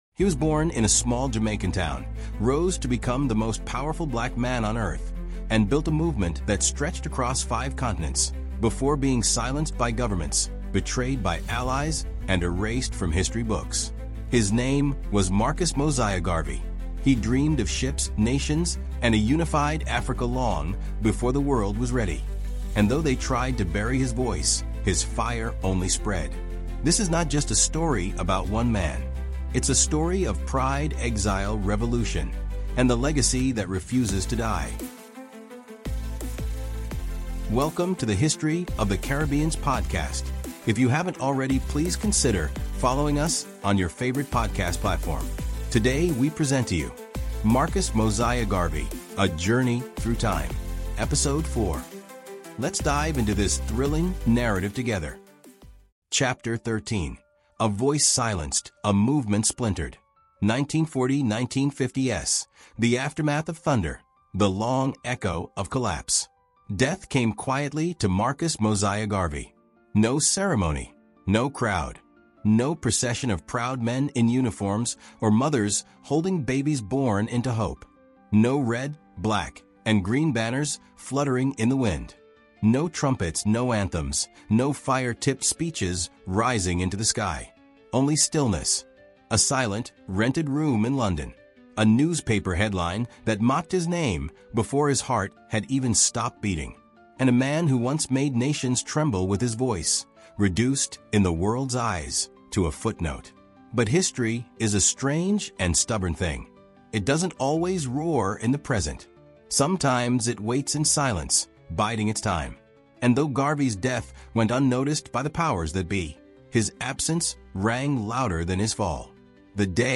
Through rich storytelling and immersive narration, discover how Garvey inspired generations—from African presidents and civil rights leaders to Rastafarians, poets, and musicians across the globe.